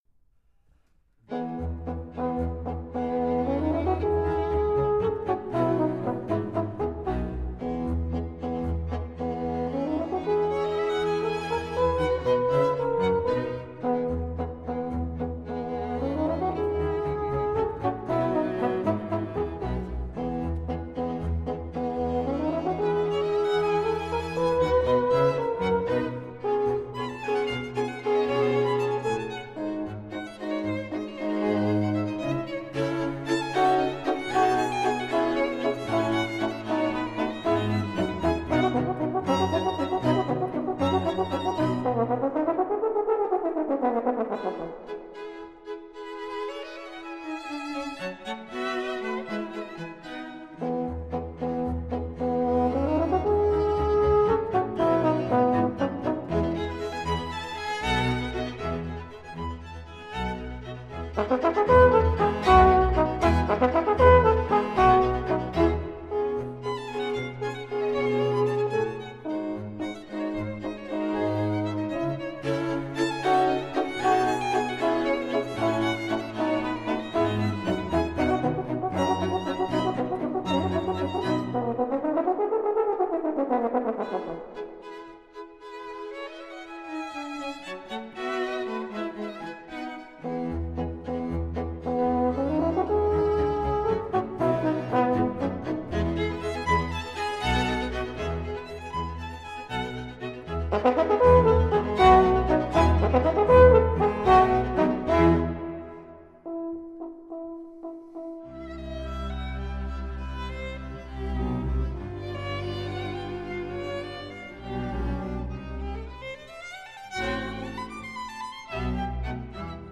horn
for horn,Violin,2 violas and bass.